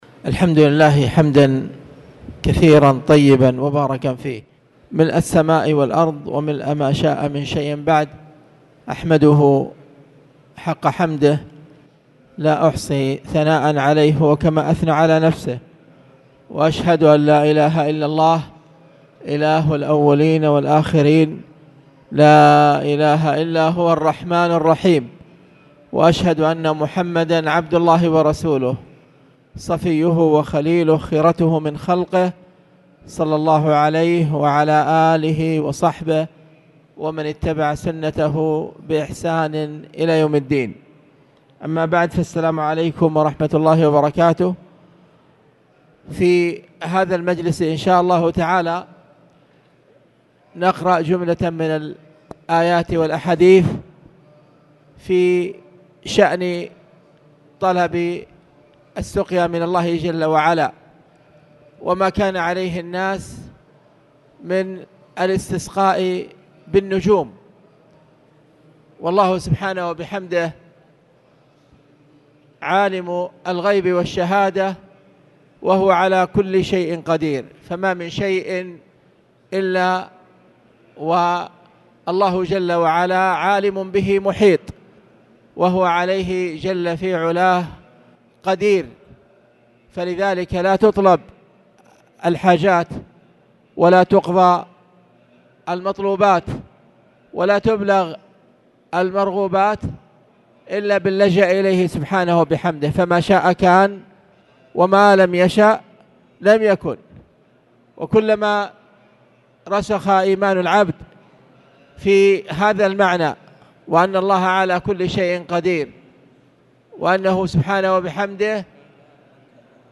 تاريخ النشر ١ شعبان ١٤٣٨ هـ المكان: المسجد الحرام الشيخ